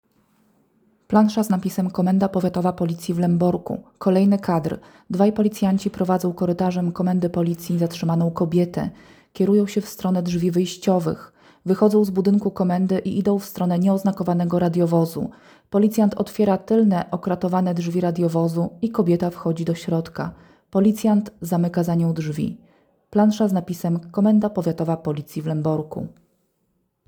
Nagranie audio audiodeskrypcja.m4a